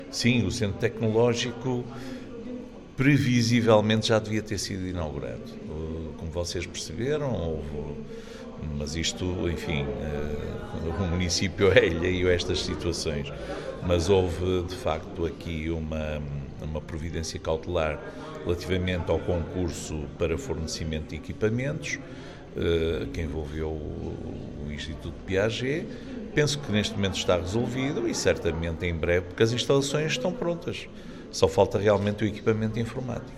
Benjamim Rodrigues, presidente da autarquia, explicou que já devia ter sido inaugurado: